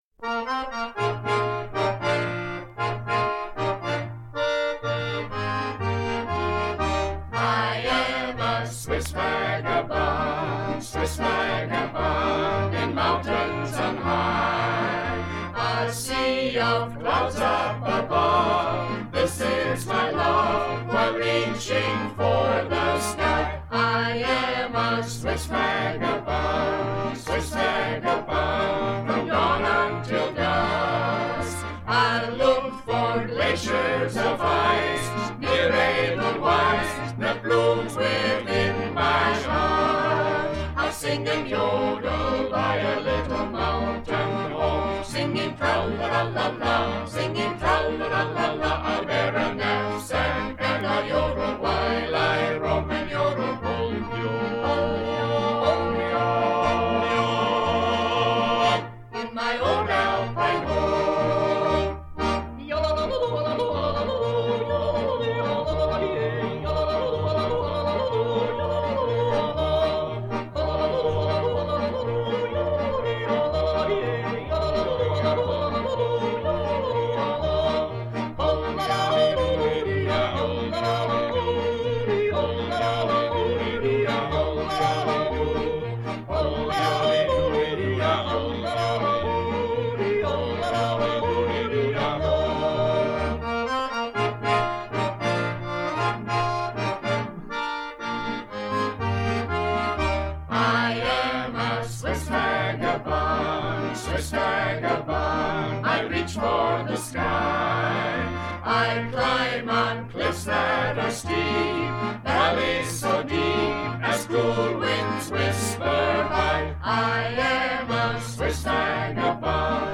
recording from LP